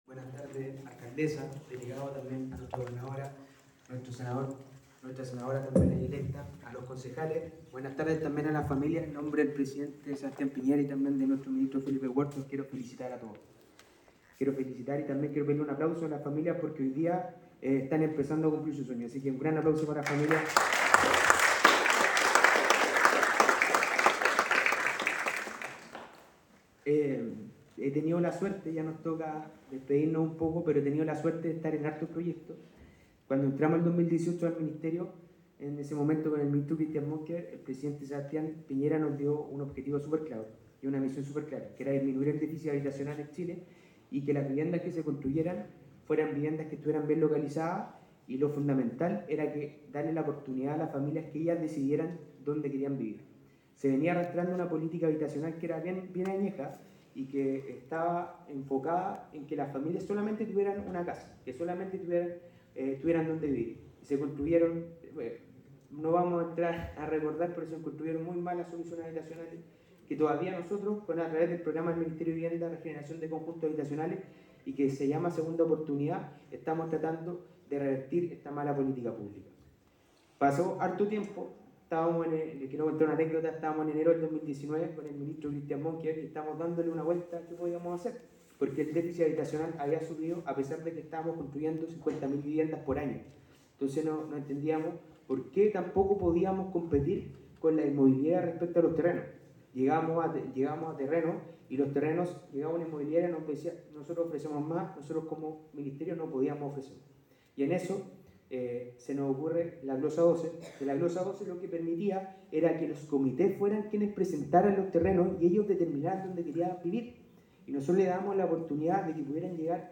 La actividad que se desarrolló en el Teatro Lord Cochrane contó con la presencia del Delegado Presidencial, Cesar Asenjo; el senador, Alfonso de Urresti; la senadora electa, María José Gatica; la Gobernadora(s), Paz de La Maza; el Seremi de Vivienda y Urbanismo, Ignacio Vidal; la Alcaldesa de Valdivia, Carla Amtmann; junto a las dirigentes y beneficiarios.
Seremi Ignacio Vidal
Seremi-Ignacio-Vidal.aac